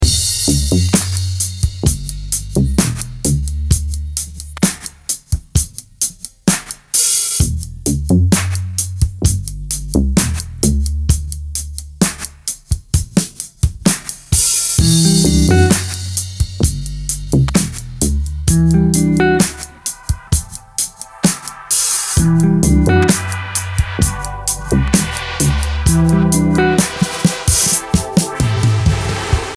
latin neo-soul tracks